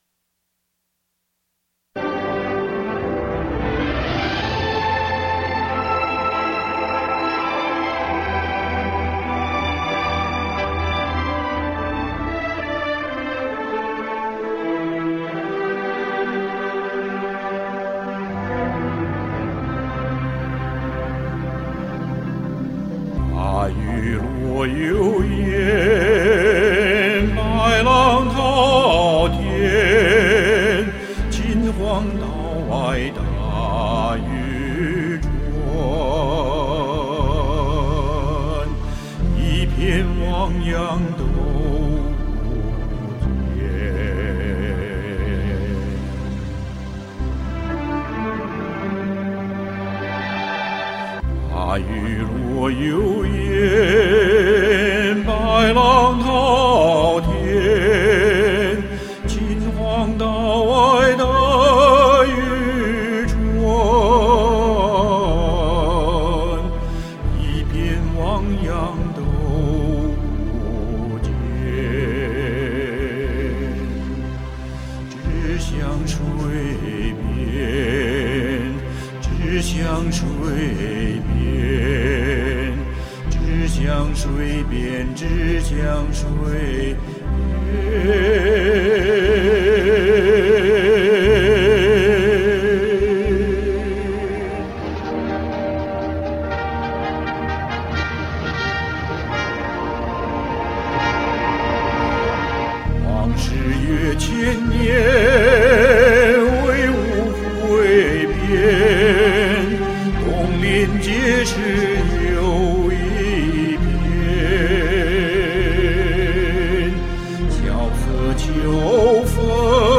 这歌我没有练好，以前发过，但是我对那一版很不满意，对这一版也不满意。